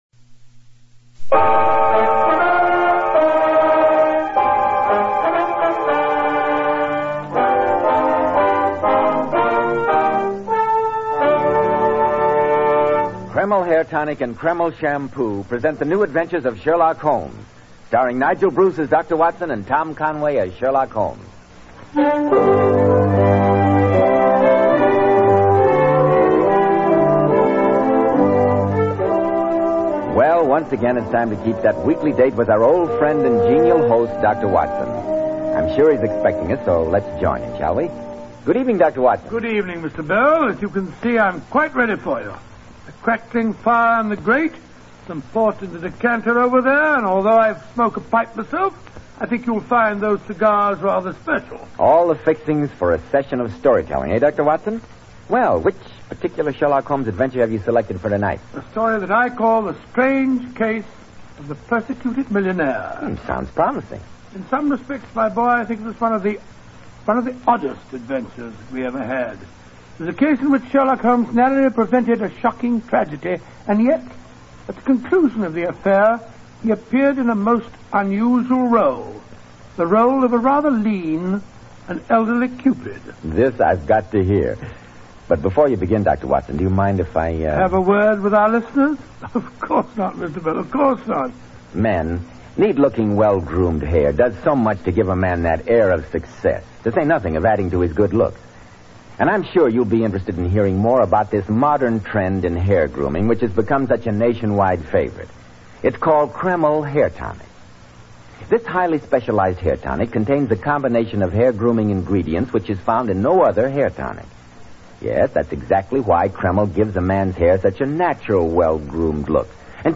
Radio Show Drama with Sherlock Holmes - The Persecuted Millionaire 1947